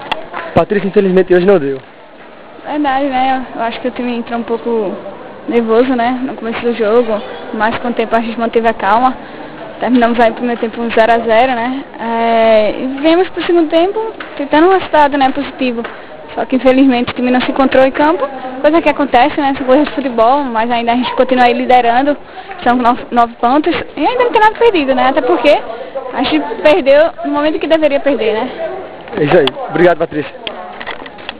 ENTREVISTA *Em Audio